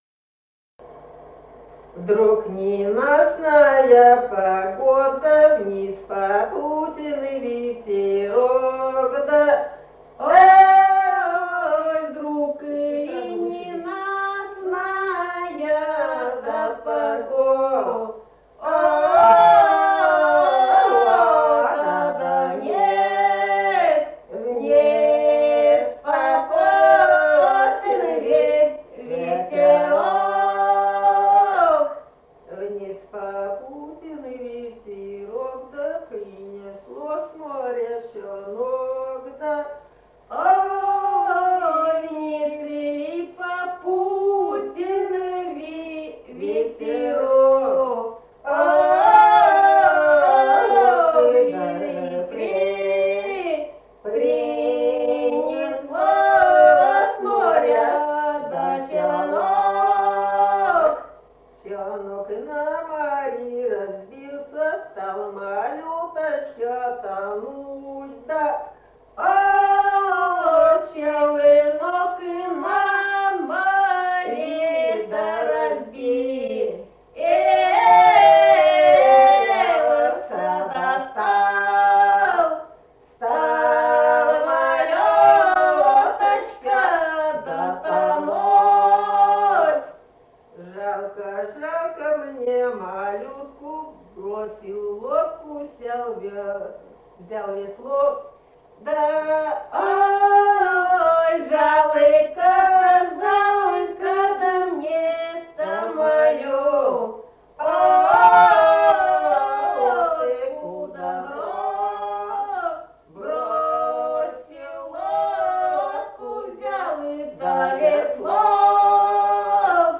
Русские песни Алтайского Беловодья 2 «Вдруг ненастная погода», лирическая.
Республика Казахстан, Восточно-Казахстанская обл., Катон-Карагайский р-н, с. Белое, июль 1978.